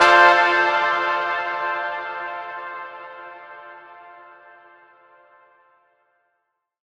Reg Horn Verb.wav